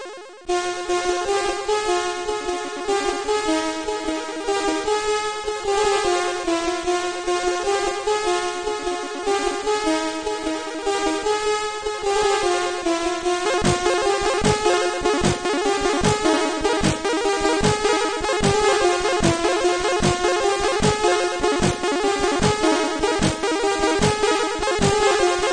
If you don’t like chip music, you may not want to proceed.